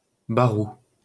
Barrou (French pronunciation: [baʁu]